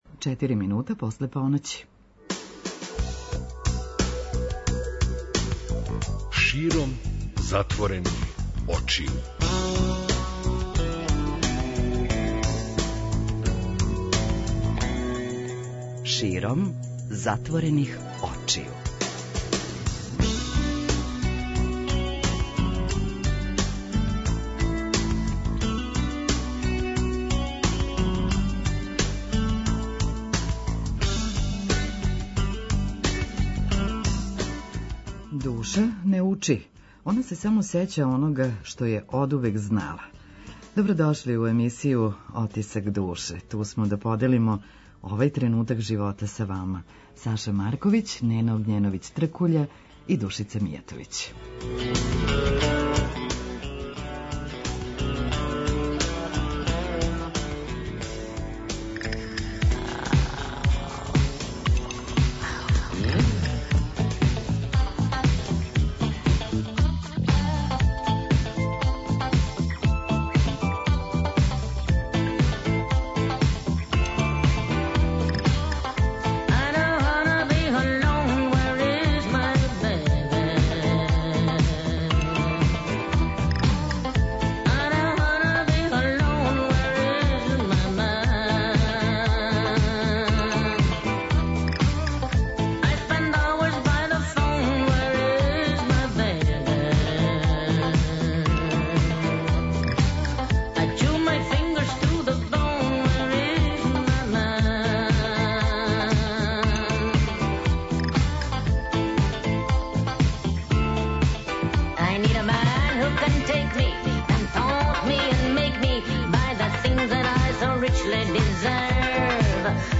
Исповест